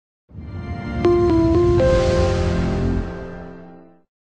大空と空港の壮大さを感じさせる。